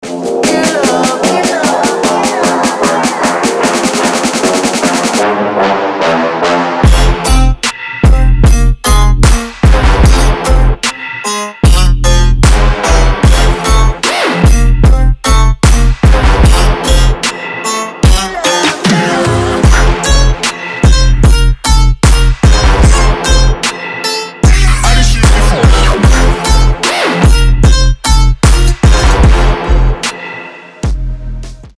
• Качество: 320, Stereo
жесткие
Electronic
Trap
качающие
Bass
Жесткий такой трэпчик))